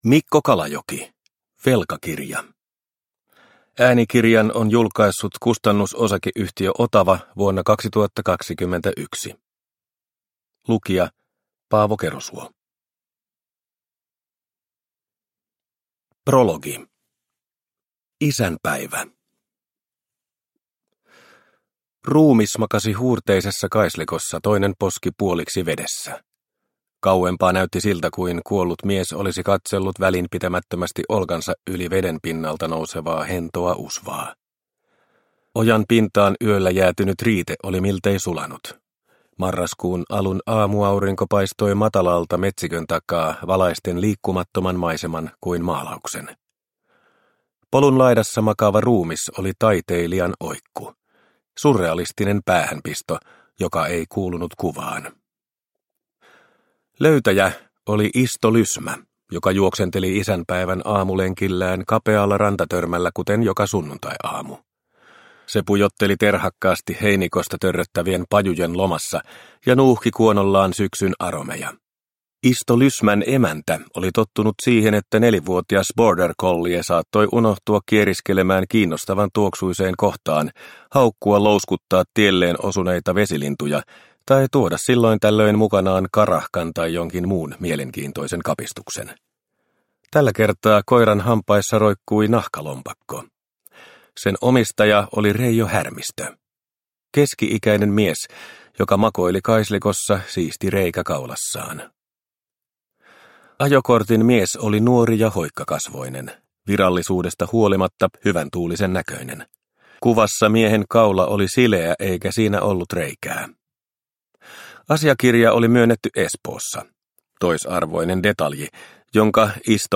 Velkakirja – Ljudbok – Laddas ner